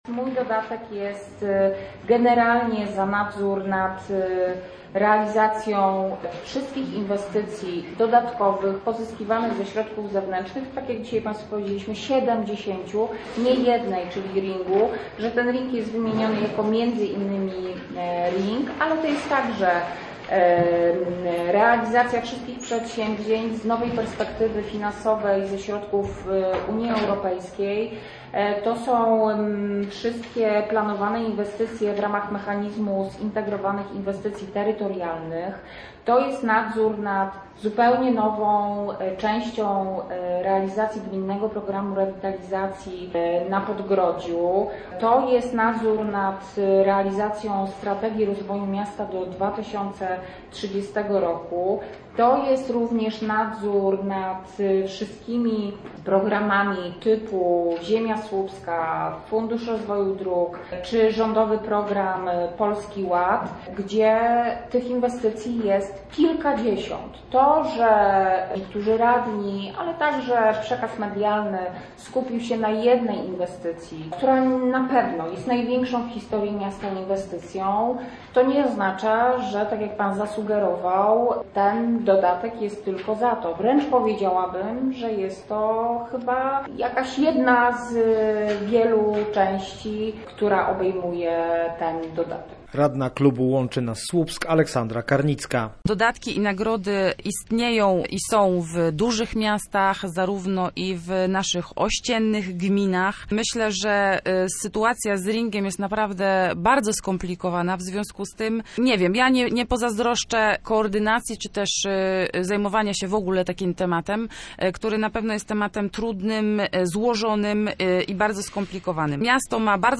Dodatkowa para oczu, uszu i rąk na pewno się przyda – mówiła wiceprezydent Słupska na konferencji prasowej.